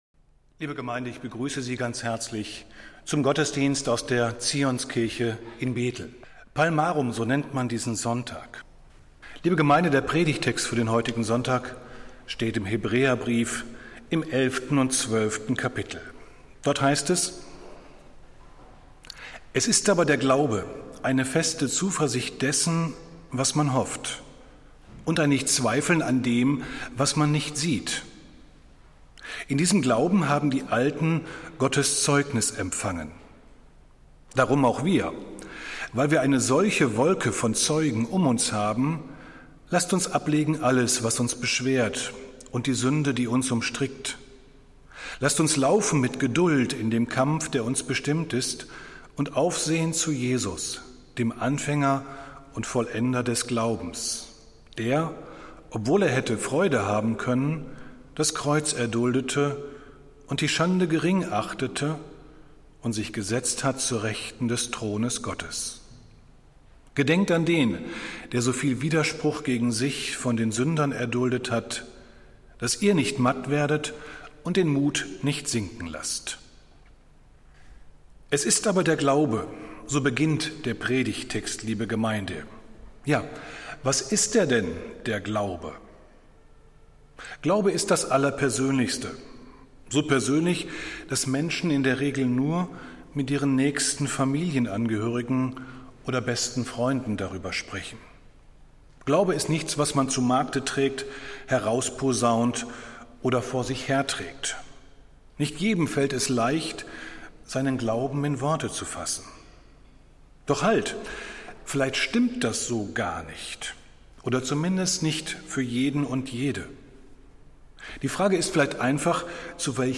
Predigt des Gottesdienstes aus der Zionskirche am Sonntag, 28.03.2021